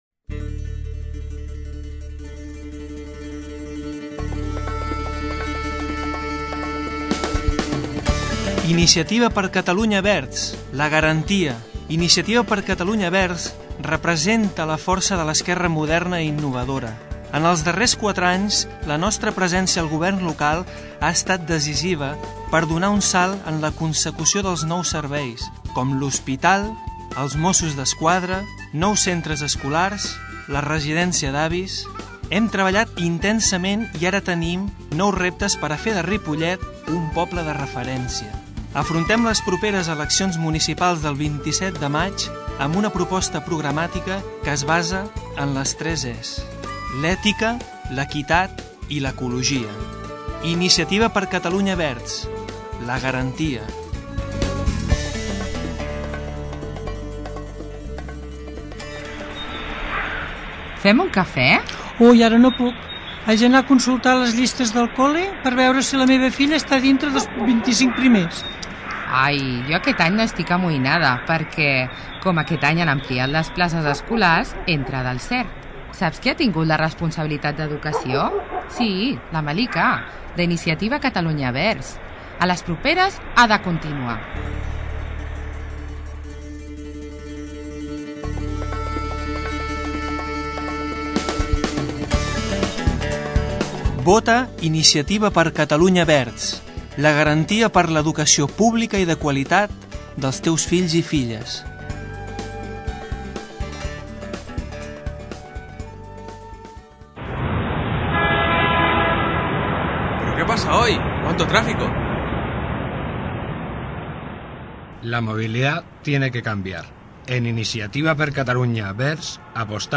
Descarregueu i escolteu l'espai radiofònic enregistrat per aquest partit als estudis de Ripollet Ràdio.